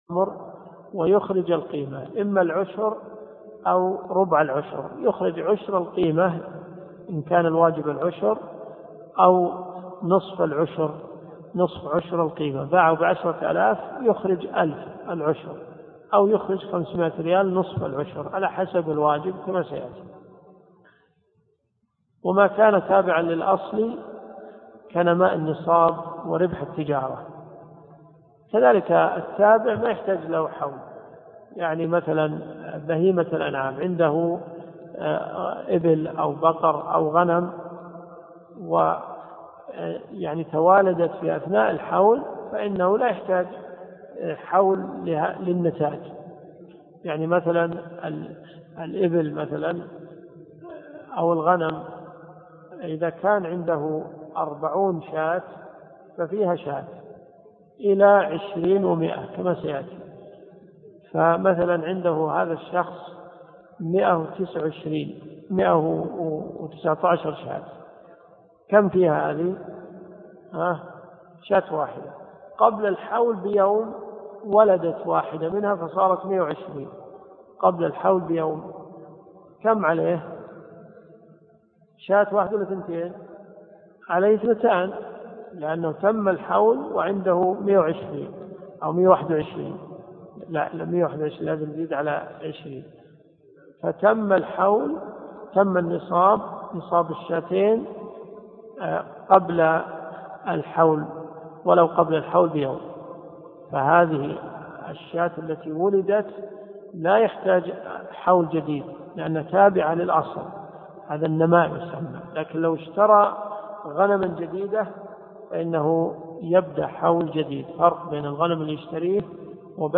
الدروس الشرعية
المدينة المنورة . جامع البلوي